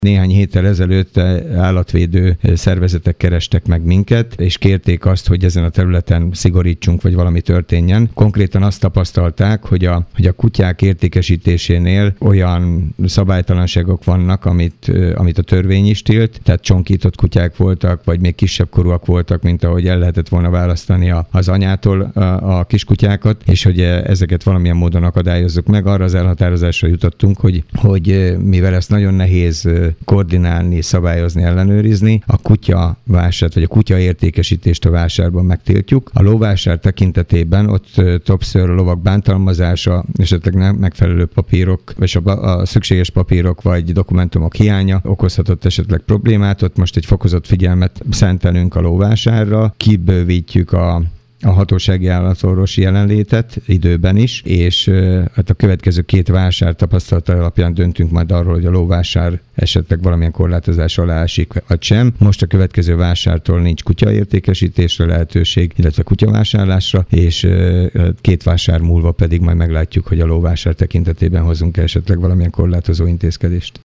Kőszegi Zoltán polgármester nyilatkozott a döntésről.